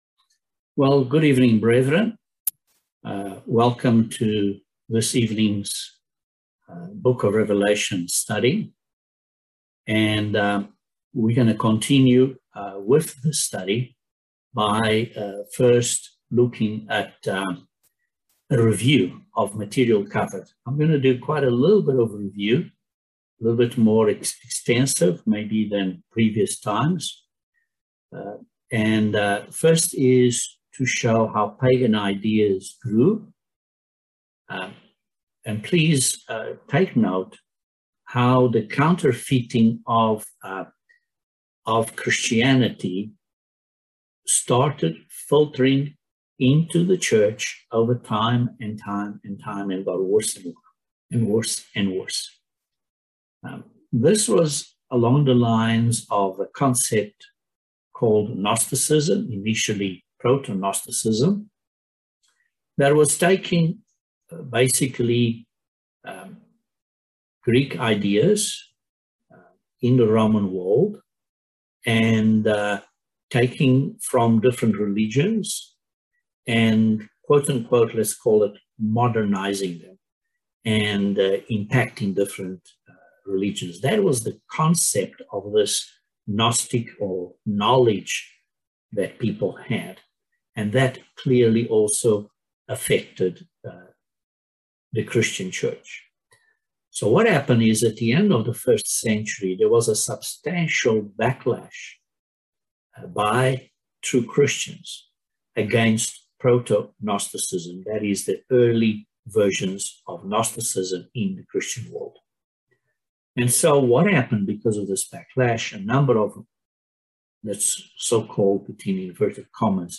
Bible Study no 6 of Revelation